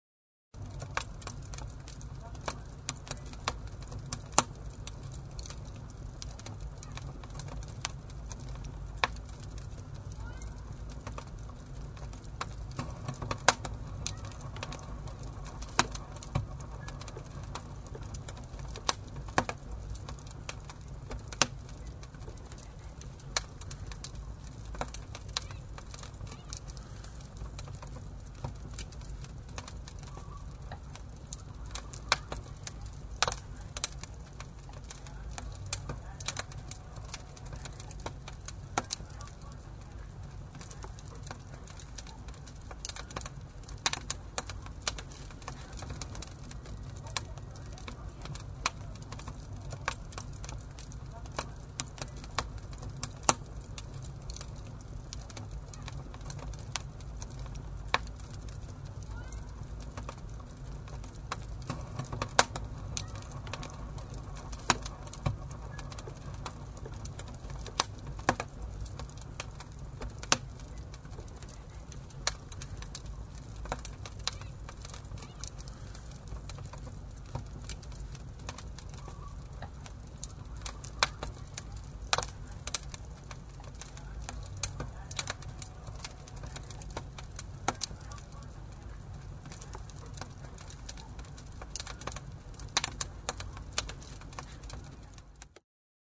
Звуки лета
Шепот летних посиделок вечером у костра